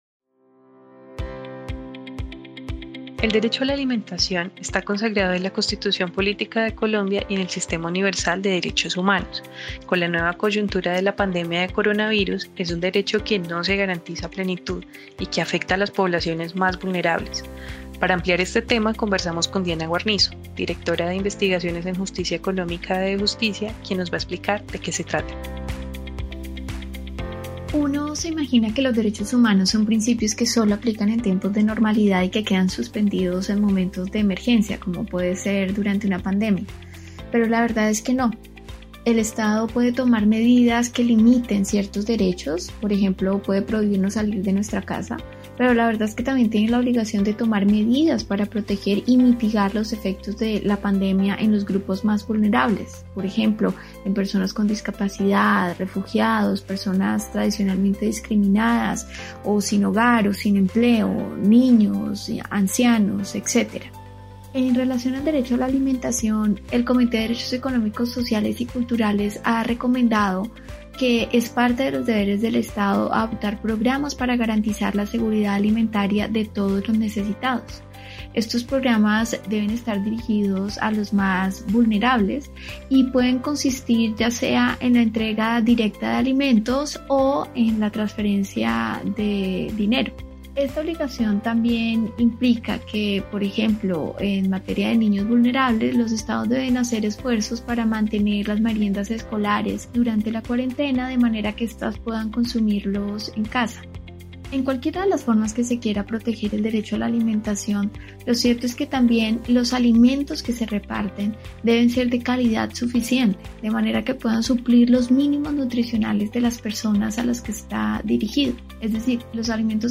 Escucha una serie de audios sobre el derecho a la alimentación, bajo la voz de diferentes expertas.